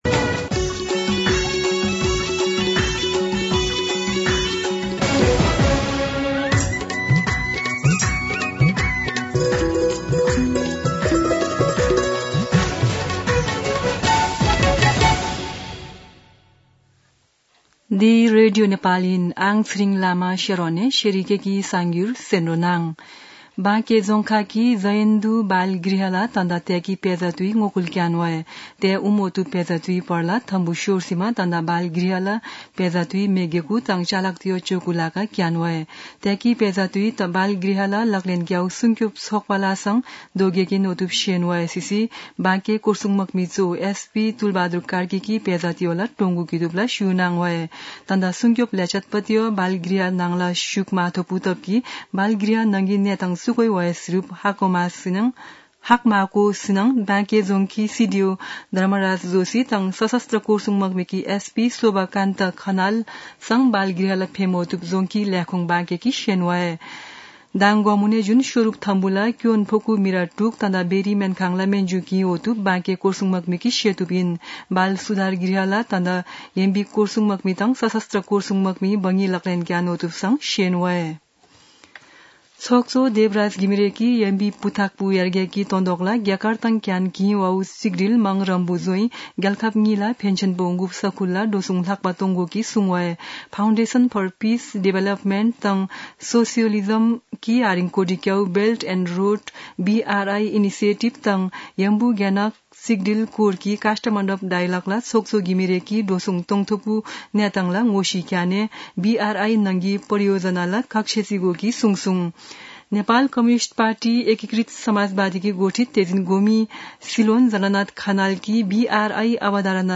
शेर्पा भाषाको समाचार : २ चैत , २०८१
Sharpa-News-12-2.mp3